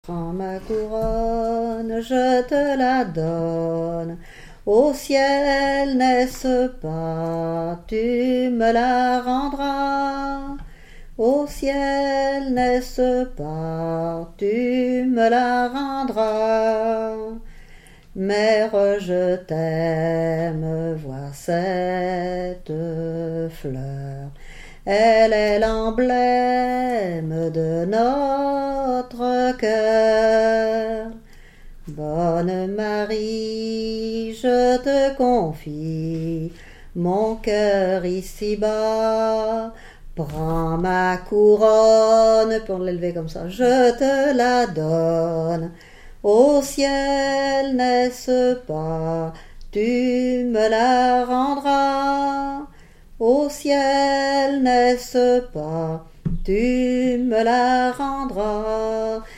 Chanté à l'occasion de la communion
cantique
Pièce musicale inédite